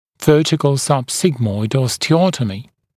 [‘vɜːtɪkl ˌsʌb’sɪgmɔɪd ˌɔstɪ’ɔtəmɪ][‘вё:тикл ˌсаб’сигмойд ˌости’отэми]вертикальная остеотомия